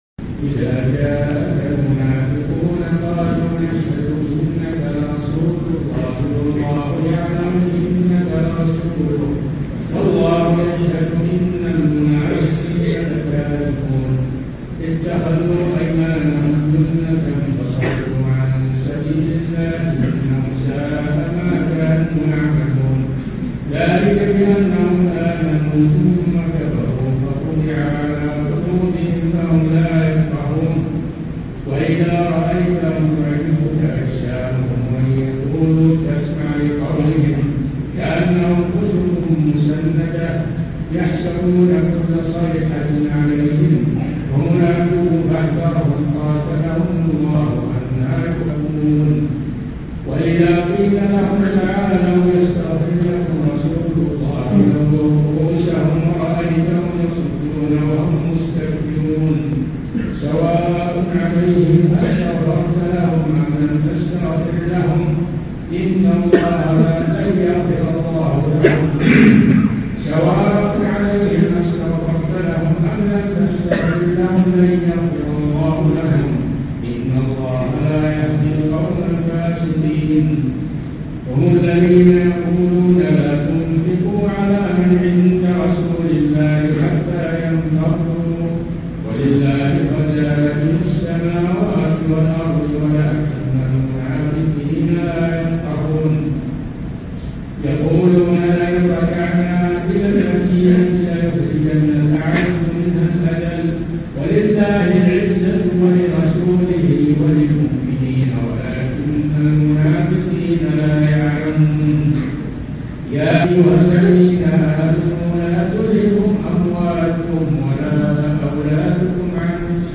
قراءات نادرة للشيخ بقية السلف ( العلامة صالح الفوزان) حفظه الله
المنافقون قراءة نادرة